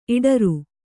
♪ iḍaru